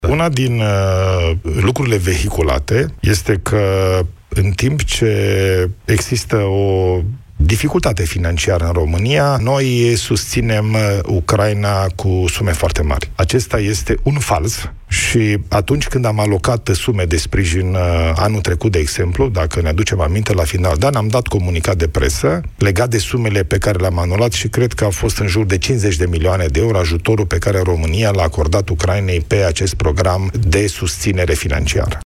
Premierul Ilie Bolojan, la Europa FM: E falsă ideea că România susține Ucraina cu sume foarte mari